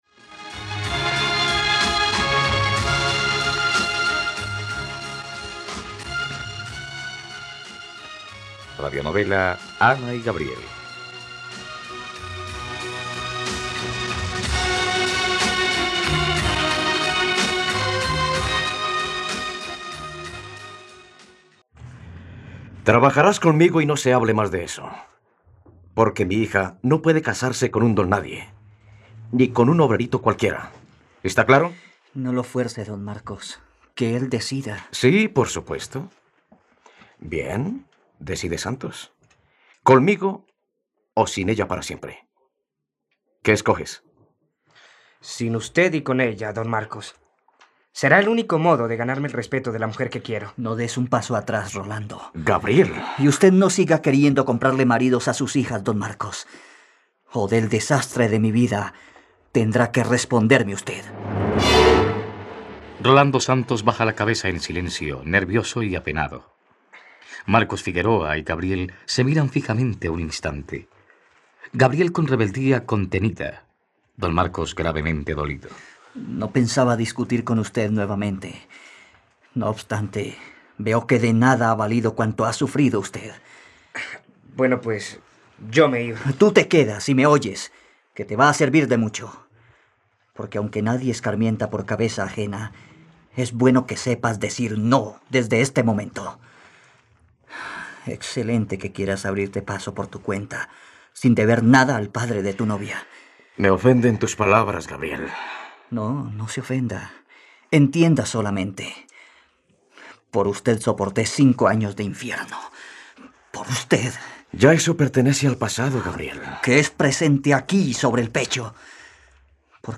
..Radionovela. Escucha ahora el capítulo 109 de la historia de amor de Ana y Gabriel en la plataforma de streaming de los colombianos: RTVCPlay.